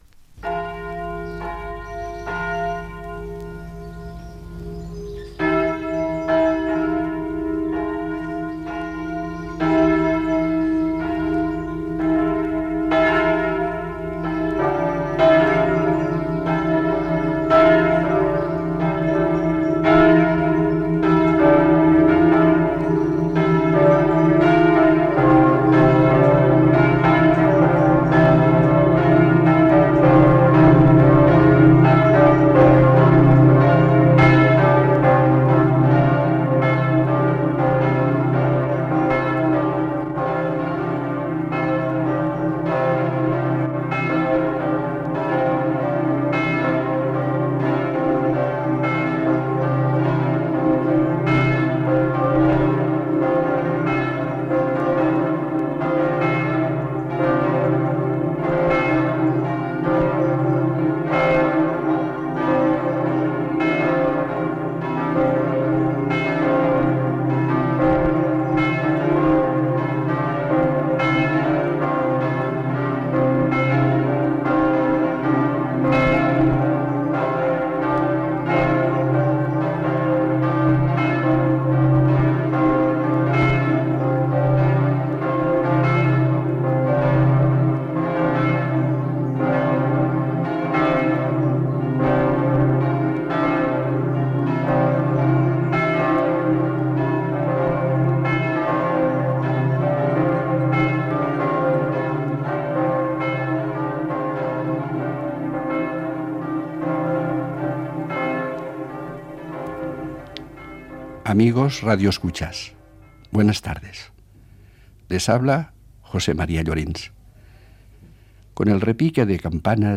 93acf00ec46a067ff4f0345b8ad0be4ff76413c9.mp3 Títol Radio 2 - Estudio de la música antigua Emissora Radio 2 Cadena RNE Titularitat Pública estatal Nom programa Estudio de la música antigua Descripció Espai dedicat a la música nadalenca. Campanes, presentació, comentari i temes musicals